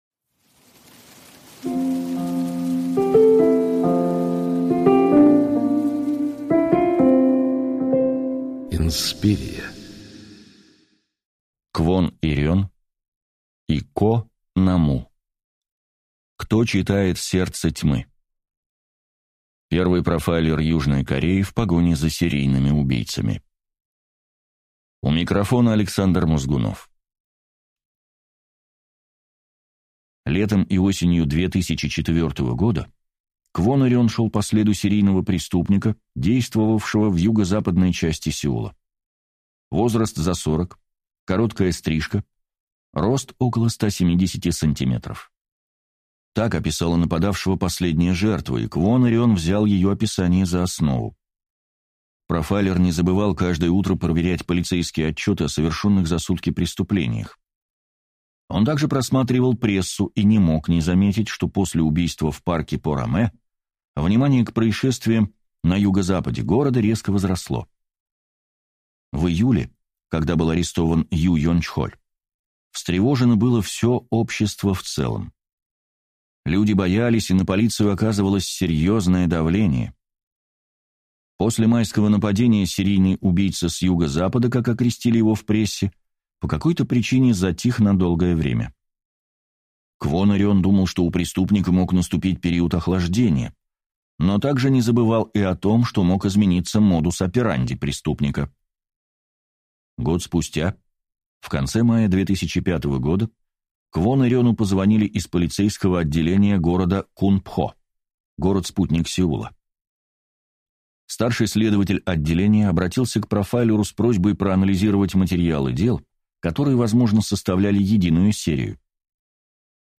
Аудиокнига Кто читает сердце тьмы. Первый профайлер Южной Кореи в погоне за серийными убийцами | Библиотека аудиокниг